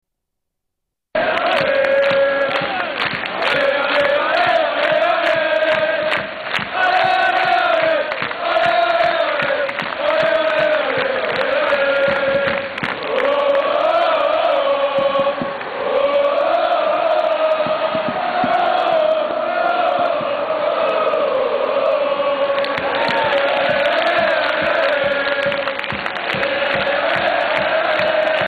Chant de supporters